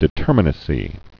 (dĭ-tûrmə-nə-sē)